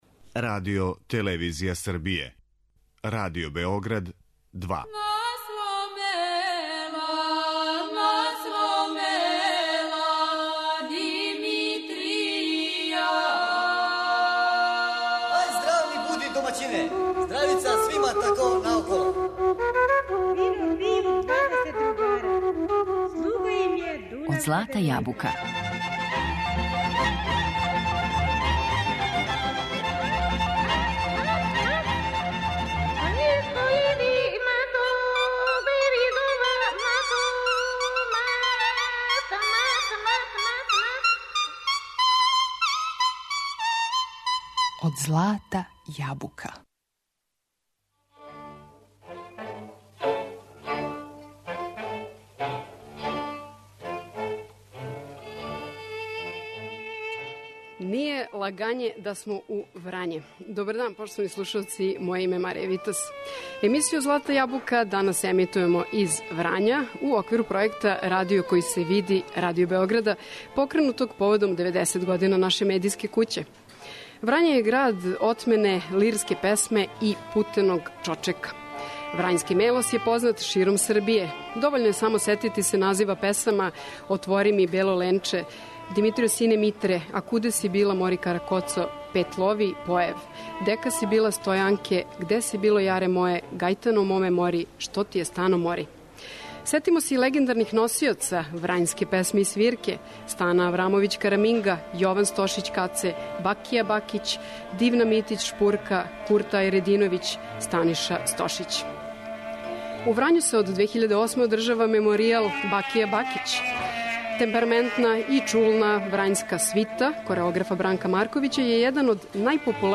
Уживо из Врања